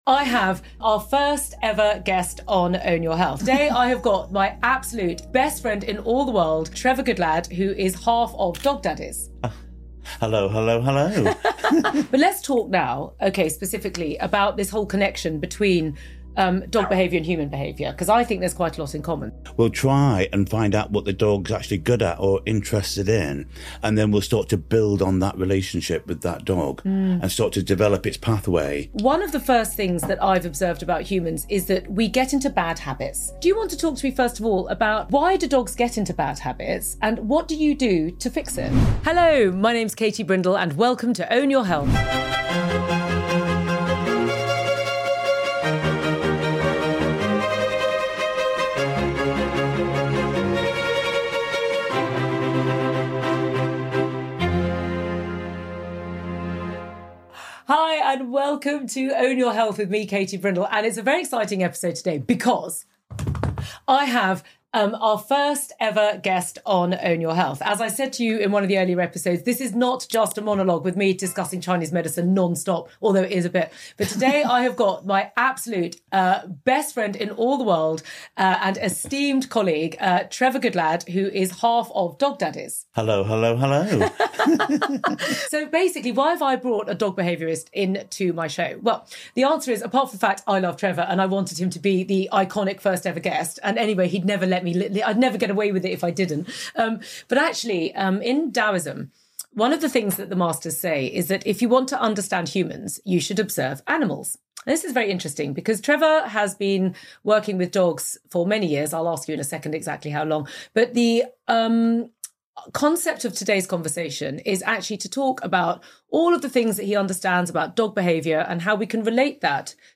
Together, they chat about ways our furry friends can teach us about owning our health. Discover how the simplicity of a dog's life can offer lessons on mindfulness and unconditional love.